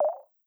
Modern UI SFX / SlidesAndTransitions
Minimize4.wav